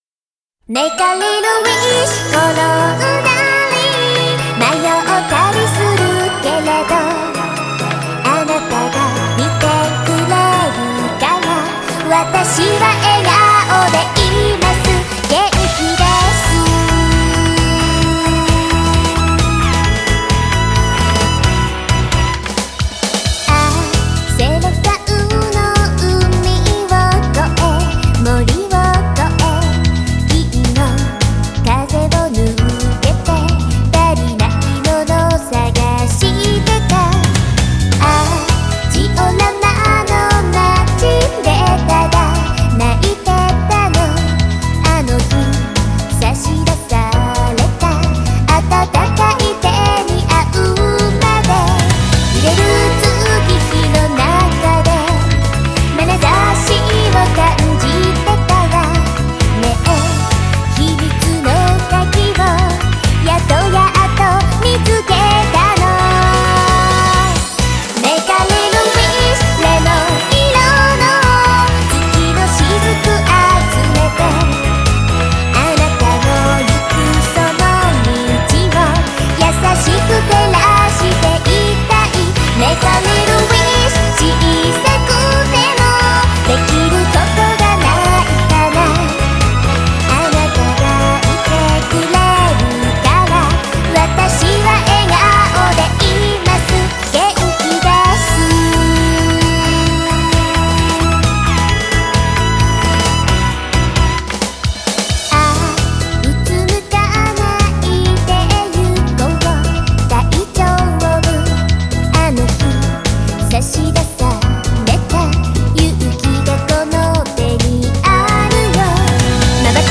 BPM66-132
Audio QualityPerfect (High Quality)